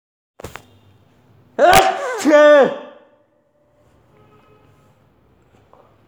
The sound of pollution is a recording of how I was afflicted by the pollutants in the air.